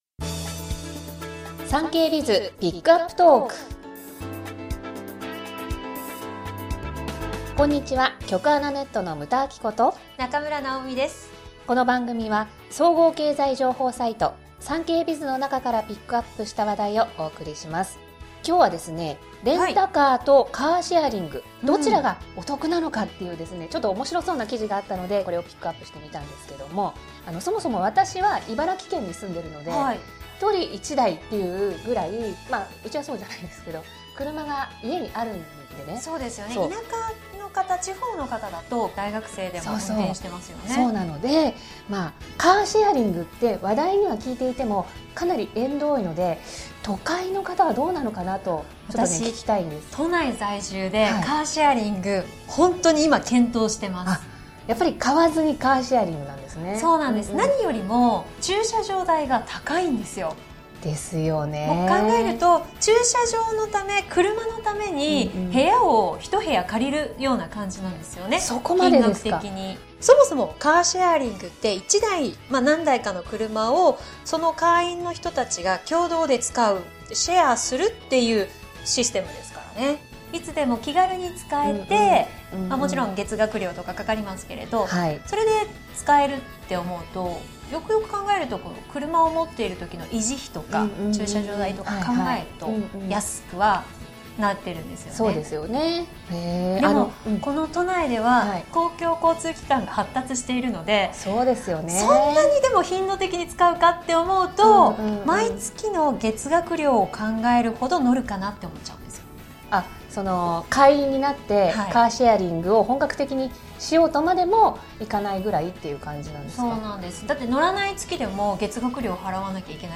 全国240名の登録がある局アナ経験者がお届けする番組「JKNTV」